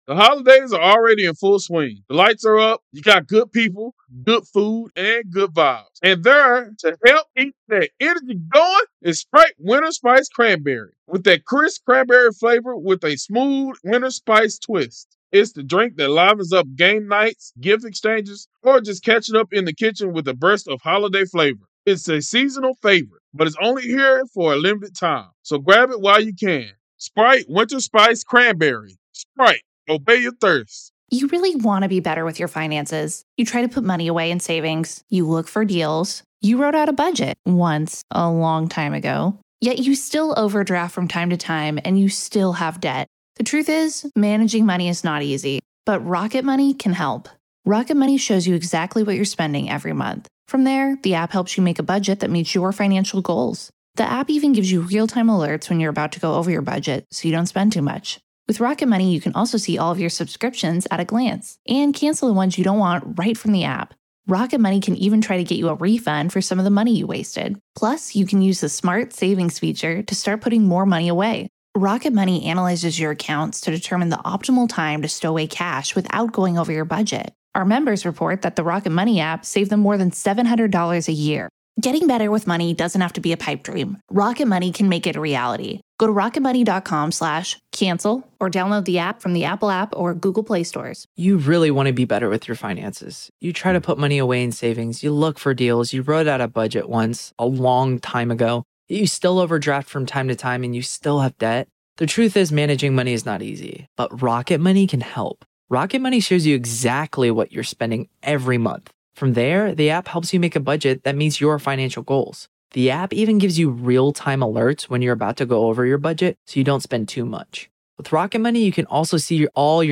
The Trial Of Alex Murdaugh | FULL TRIAL COVERAGE Day 15 - Part 1